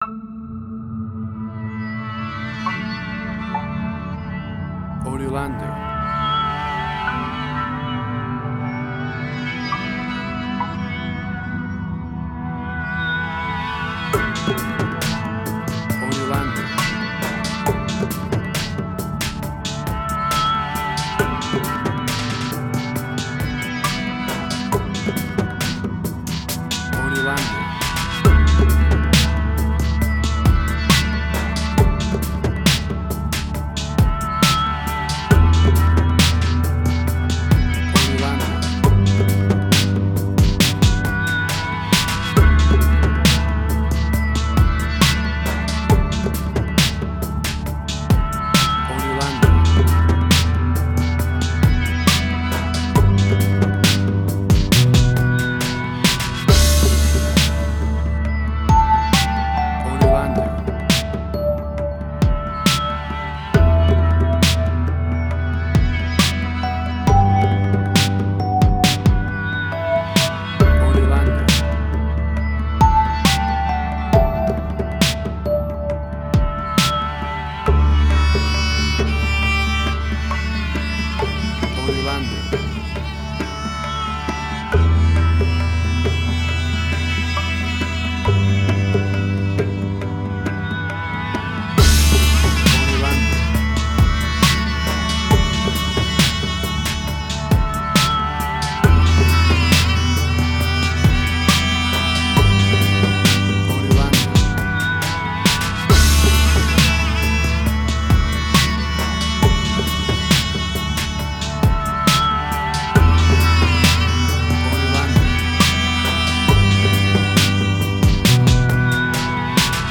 Indian Fusion
Tempo (BPM): 68